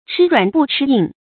吃软不吃硬 chī ruǎn bù chī yìng
吃软不吃硬发音